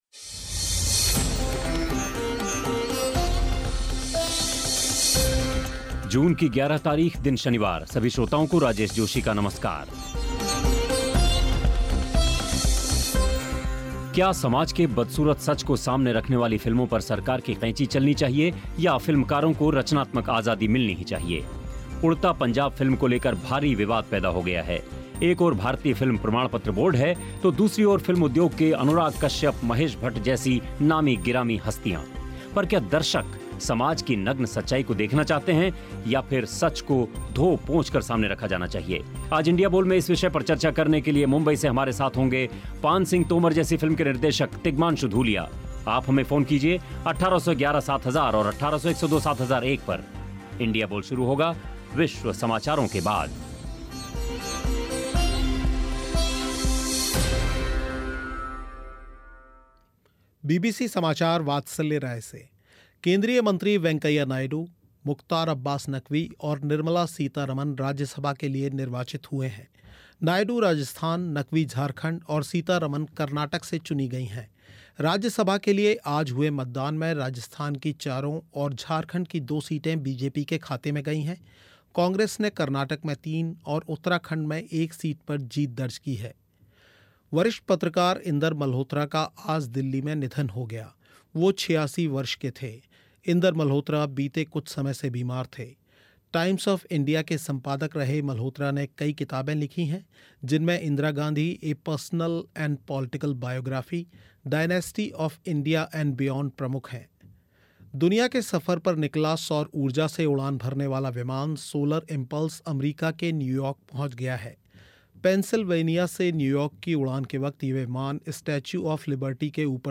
इस बार इंडिया बोल में इसी विषय पर हुई चर्चा. कार्यक्रम में शामिल हुए तिगमांशु धुलिया और बीबीसी श्रोता.